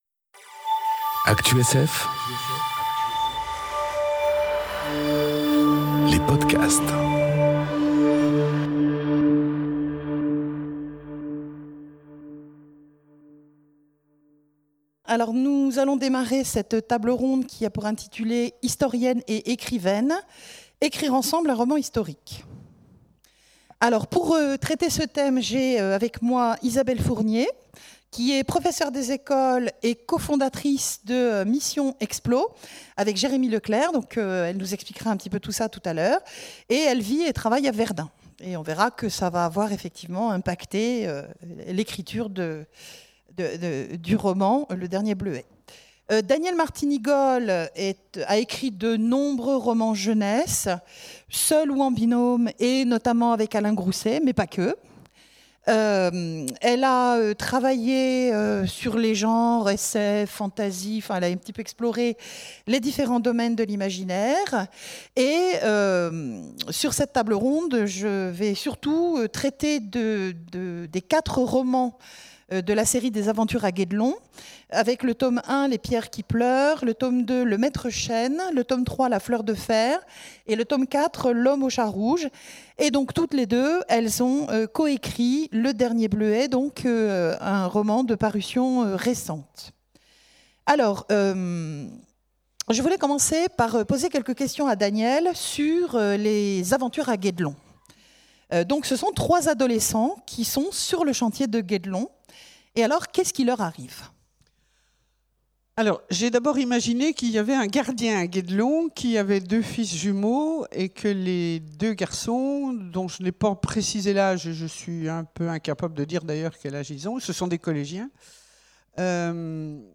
Conférence Historienne et écrivaine : écrire à deux un roman historique ! enregistrée aux Imaginales 2018